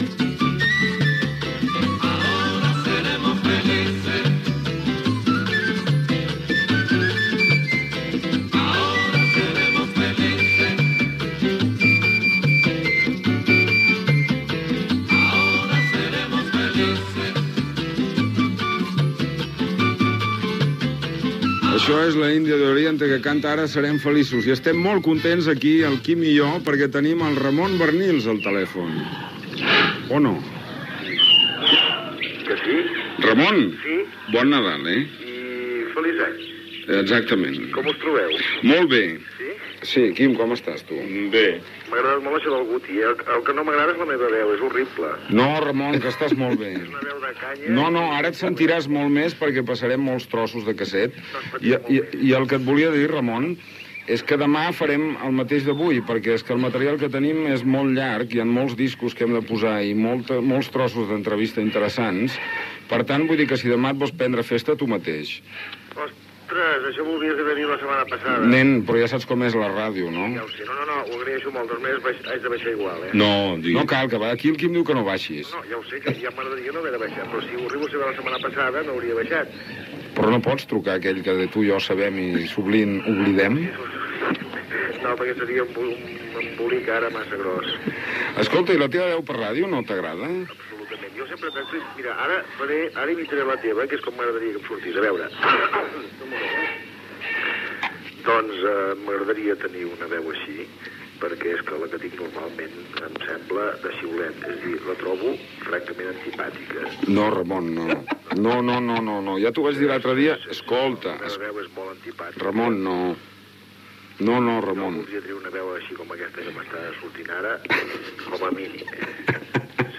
Tema musical, diàleg amb Ramon Barnils per telèfon sobre la seva veu.
Entreteniment